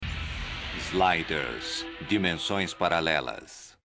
Der Titel der 1. Staffel lautet in Brasilien natürlich auch Sliders - Dimensões Paralelas und wird bei der Einblendung des Sliders-Schriftzuges von einer männlichen Stimme genannt.